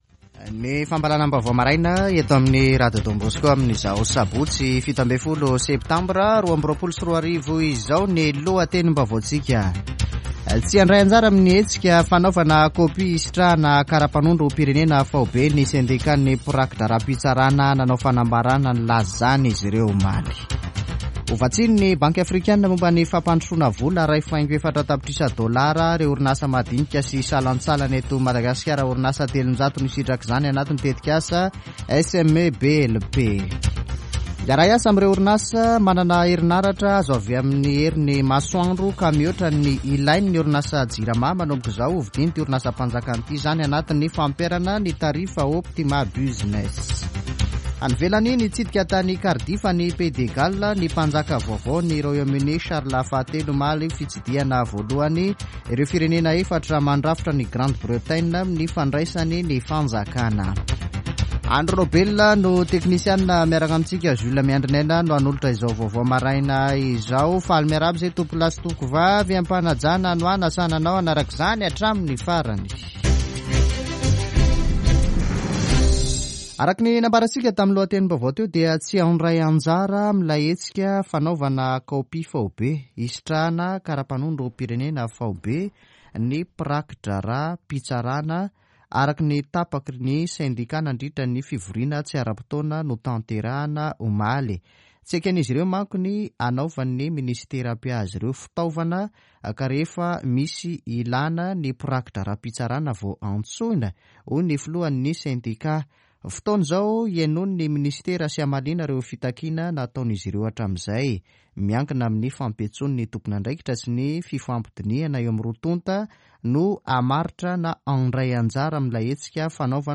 [Vaovao maraina] Sabotsy 17 septambra 2022